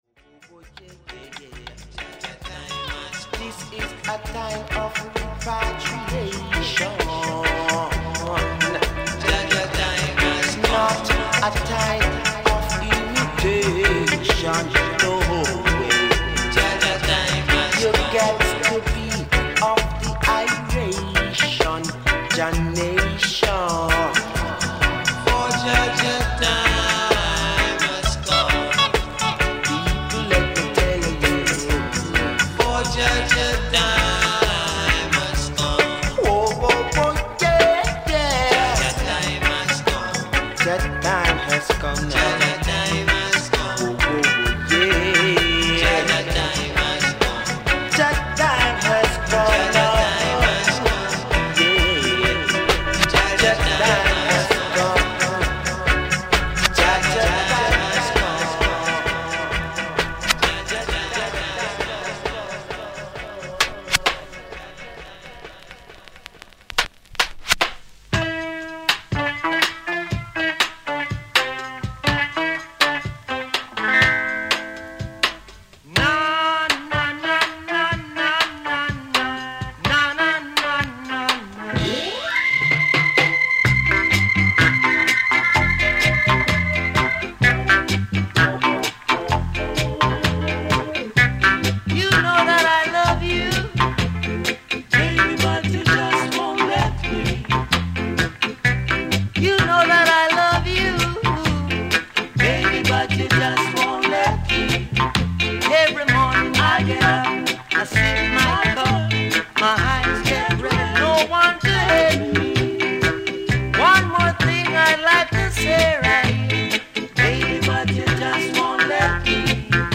▶PLAY DIGEST